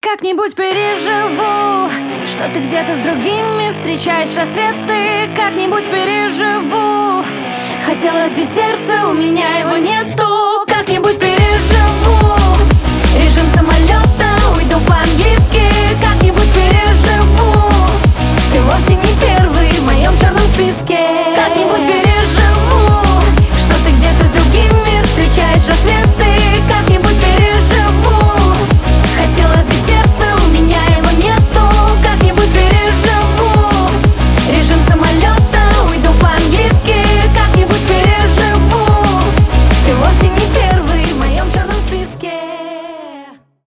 дуэт , рок
pop rock